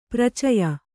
♪ pracaya